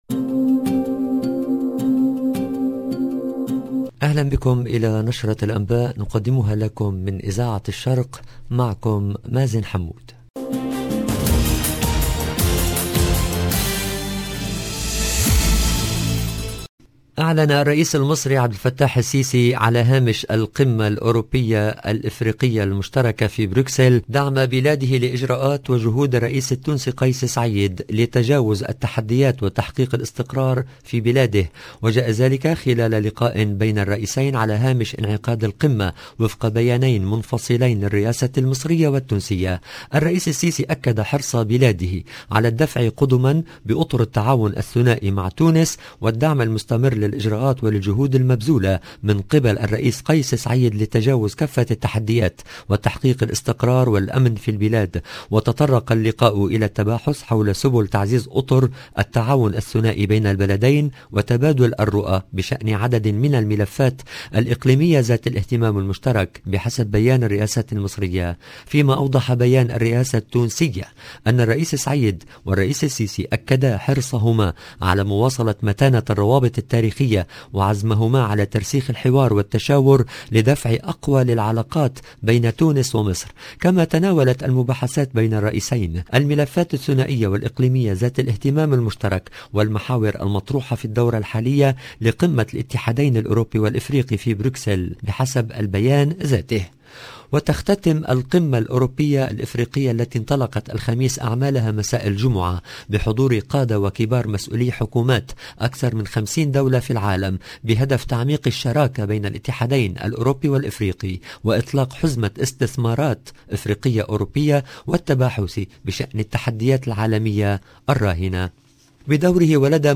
LE JOURNAL DU SOIR EN LANGUE ARABE DU 18/02/22